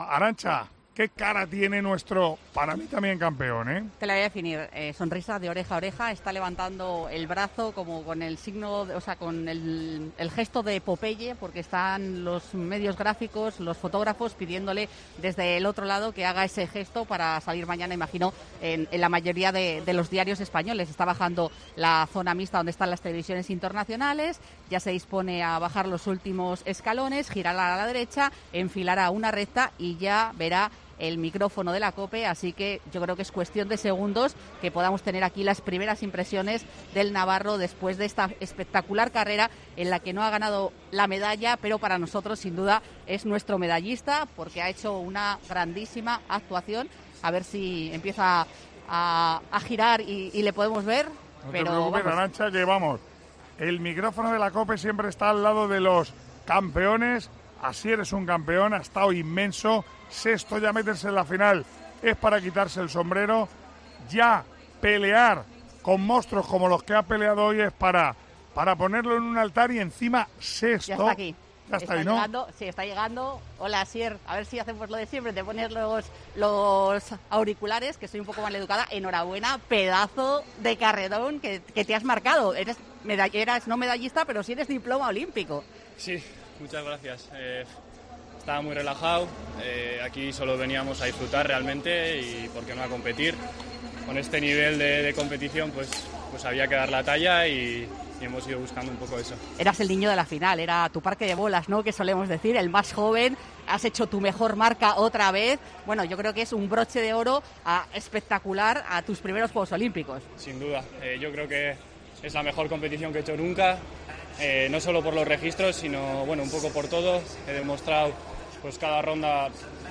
El atleta español finalizó sexto en la final de los 110 metros vallas y, tras terminar la prueba, pudo hablar con su cuadrilla de amigos en el micrófono de Tiempo de Juego.